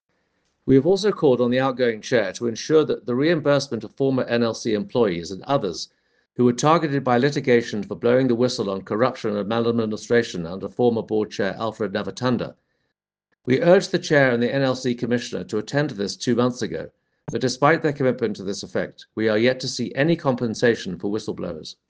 Soundbites by Toby Chance MP